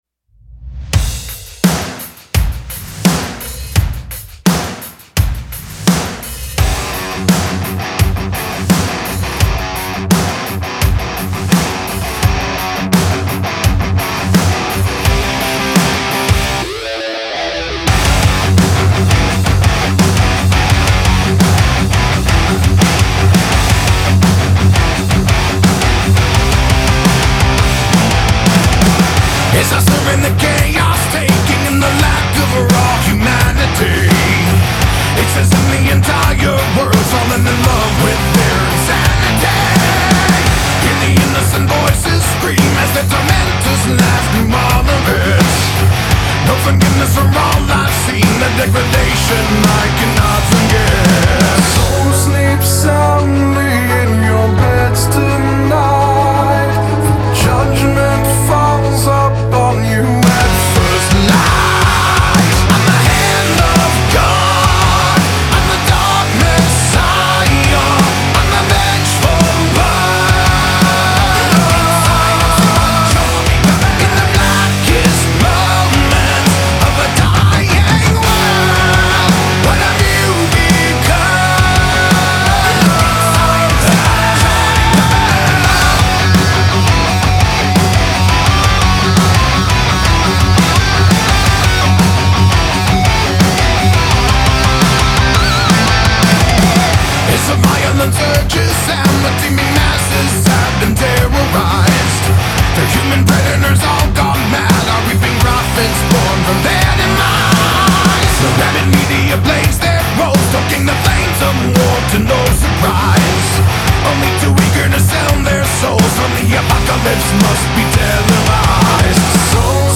• Категория:Энергичная музыка